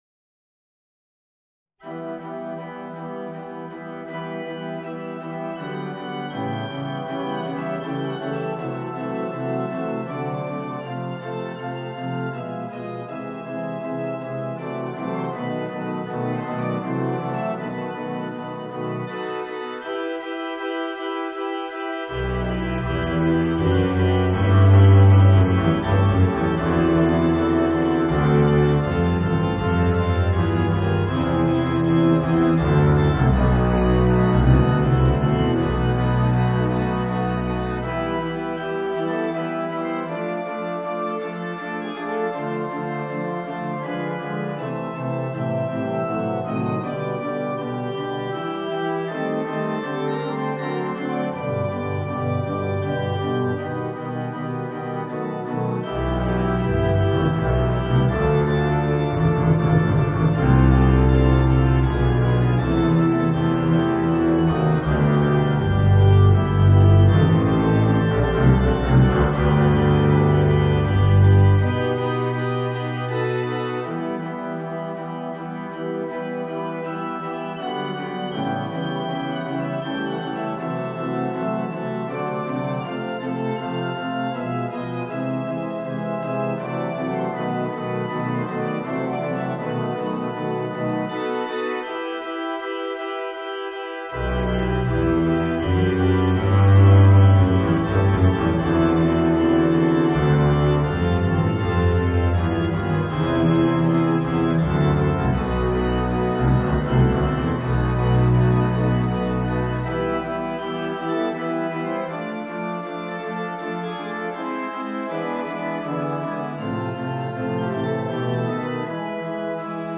Voicing: Contrabass and Organ